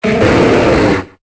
Cri de Terrakium dans Pokémon Épée et Bouclier.